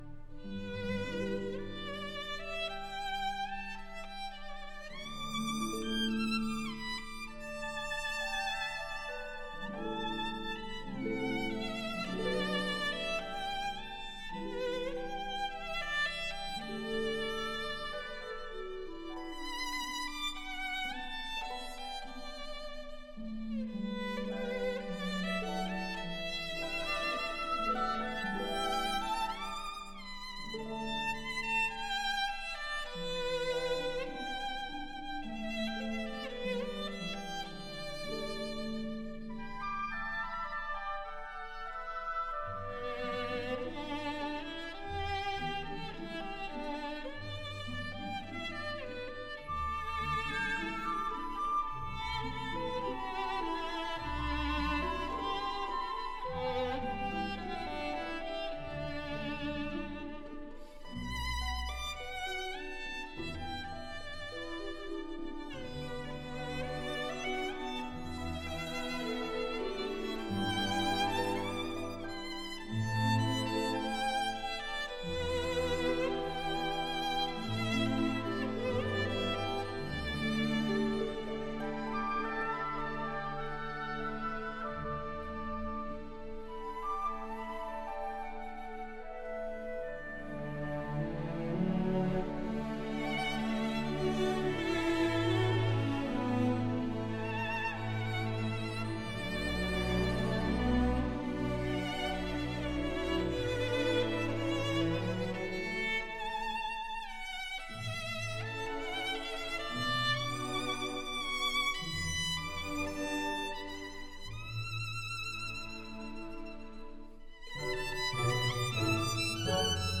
音色鲜艳无匹 透出一种迷人的光泽 音量宏伟
小提琴演奏